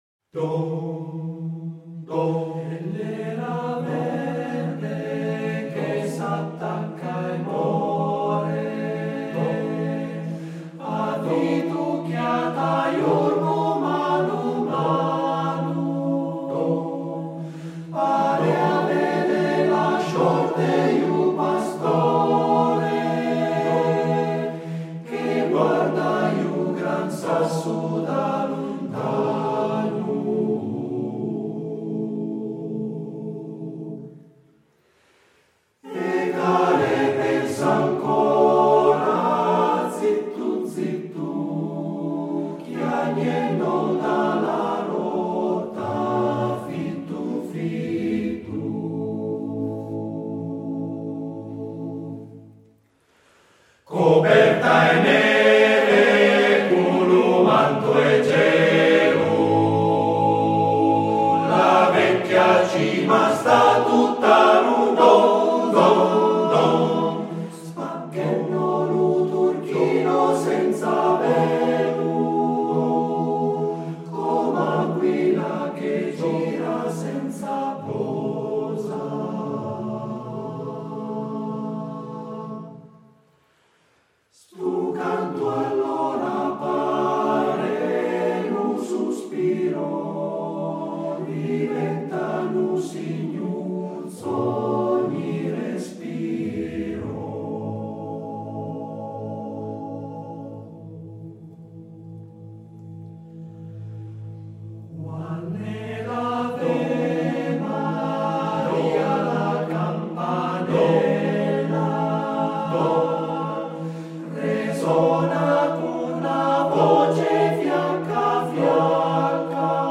Esecutore: Coro CAI Uget